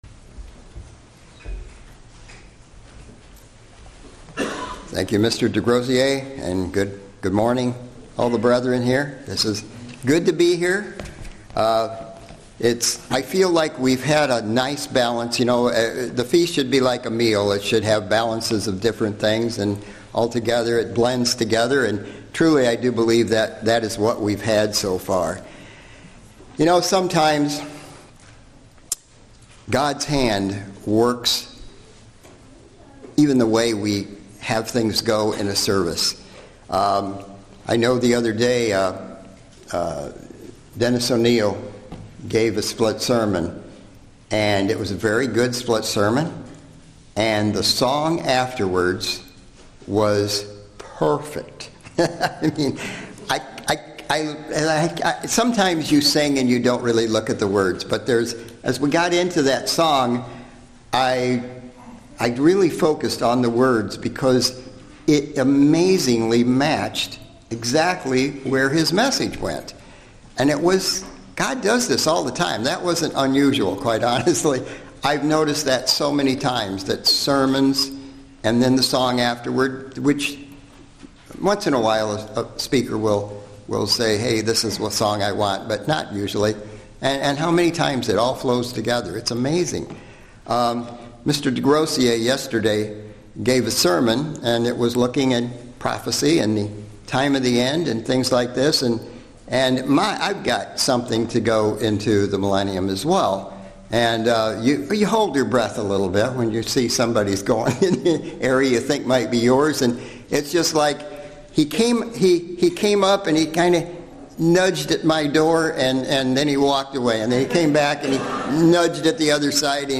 Feast of Tabernacles Sermon Millennium rebellion against God Studying the bible?